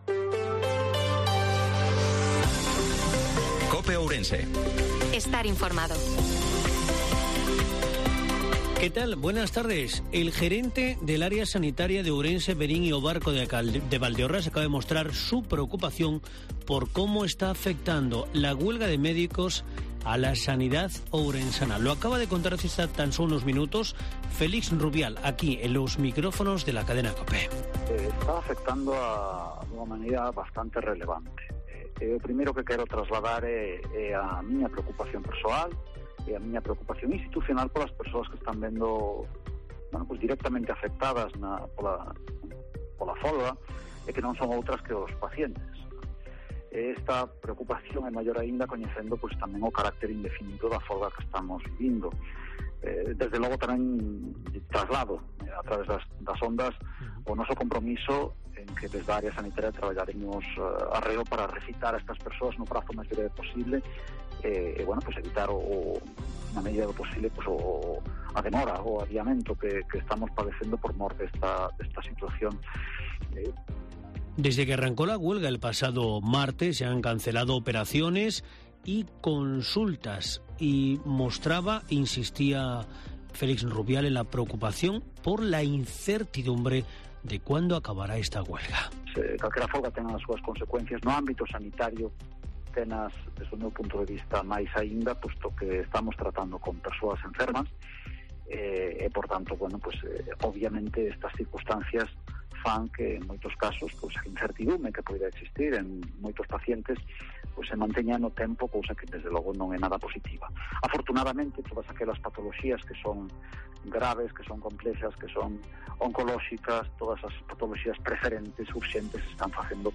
INFORMATIVO MEDIODIA COPE OURENSE-14/04/2023